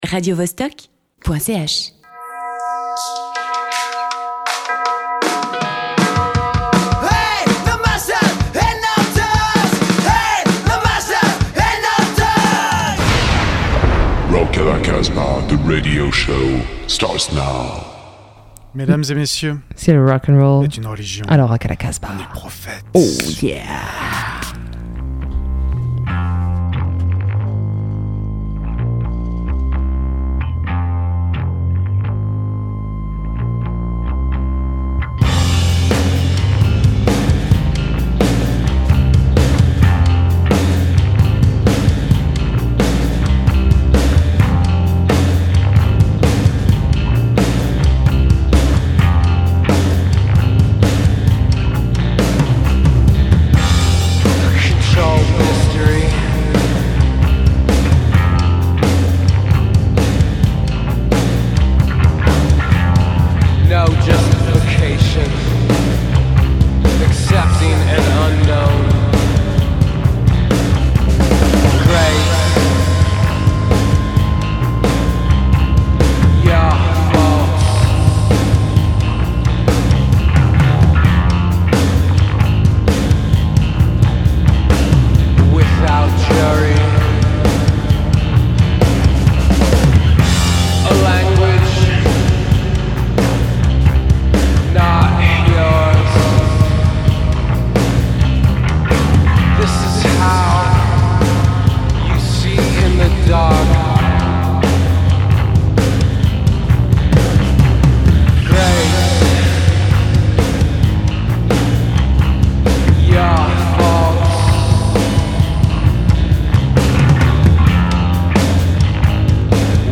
C’est une émission de rock underground qui laisse la part belle aux scènes émergentes rock, garage, blues, punk.